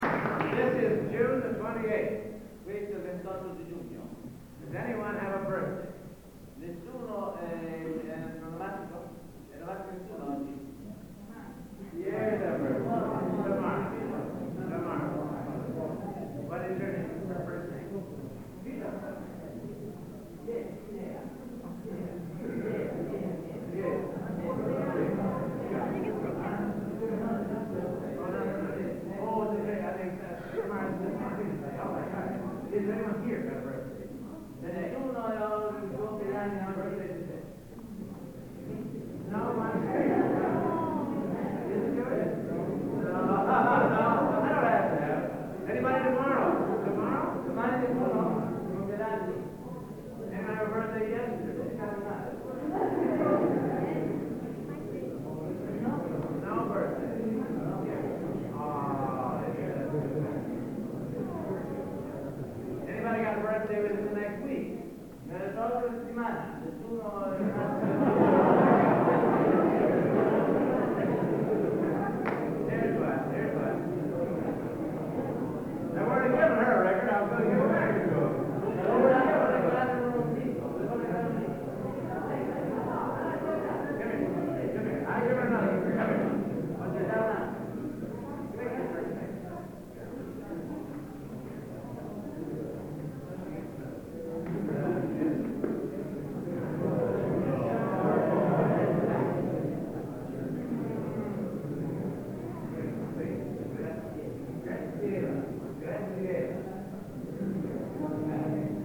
Location: Florence, Italy
Genre: | Type: Director intros, emceeing